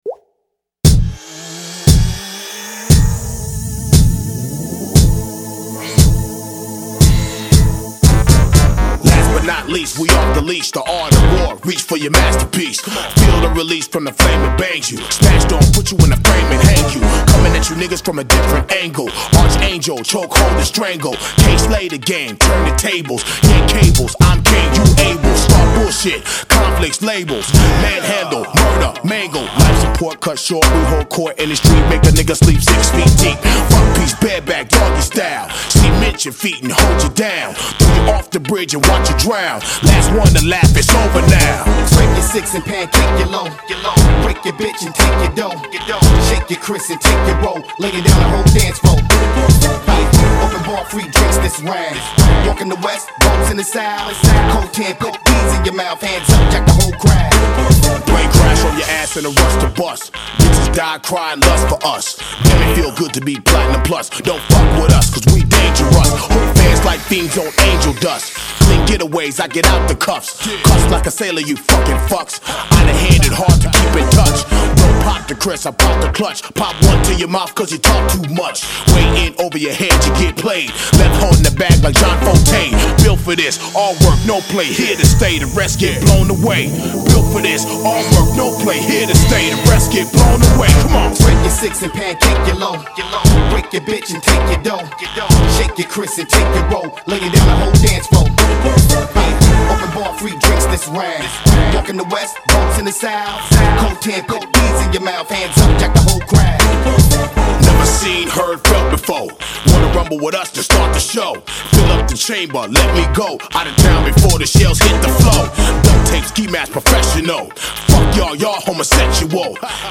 • Category: Sound 5.1